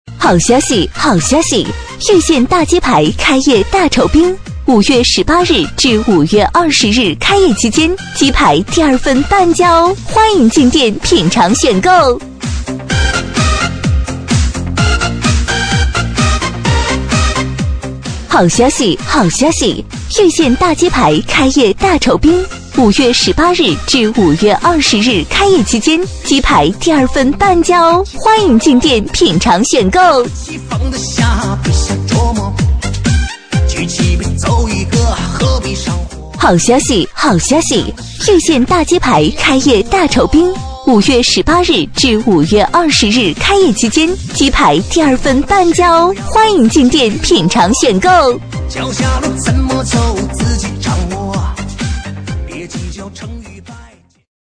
B类女36|【女36号促销】遇见大鸡排开业
【女36号促销】遇见大鸡排开业.mp3